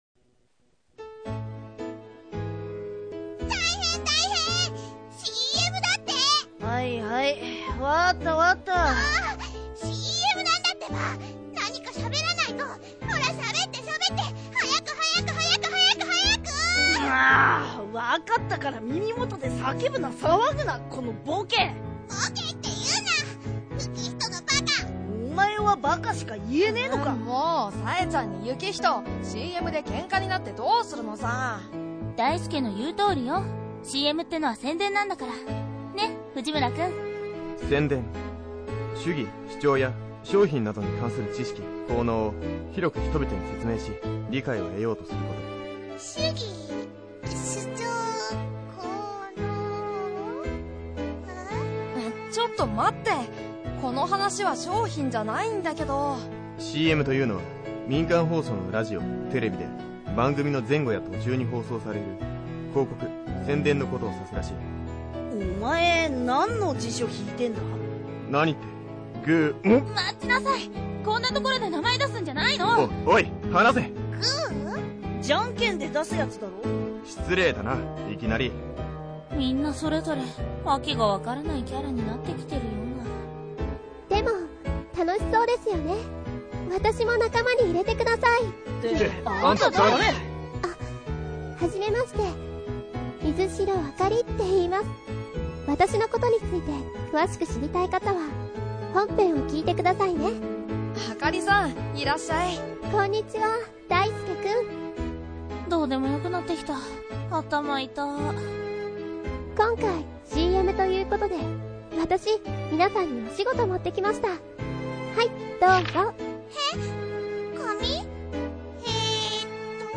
ボイスドラマ